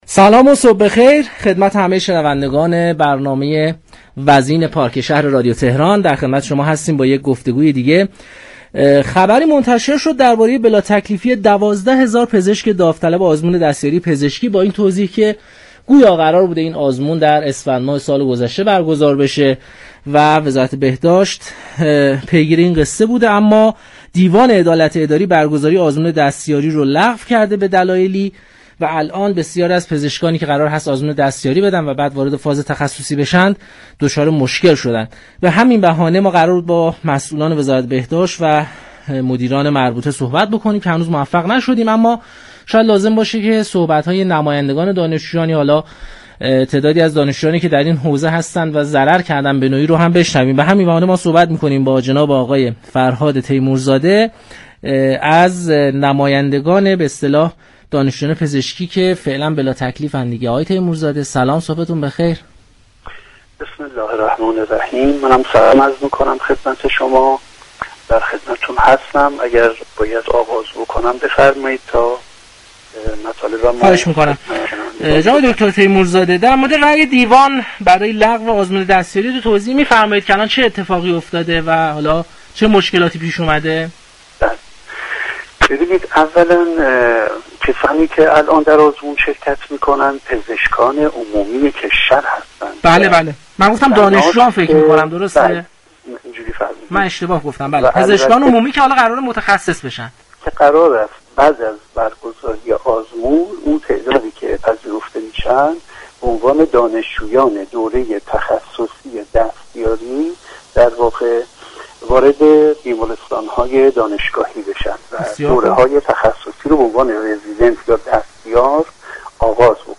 به گزارش پایگاه اطلاع‌رسانی رادیو تهران، 12 هزار پزشك آزمون دستیاری پزشكی هنوز بلاتكلیف هستند و از طرفی دیوان عدالت اداری برگزاری آزمون دستیاری در اسفند 1400 را لغو كرد اما هنوز تاریخ دقیق برگزاری این آزمون، اعلام نشده است.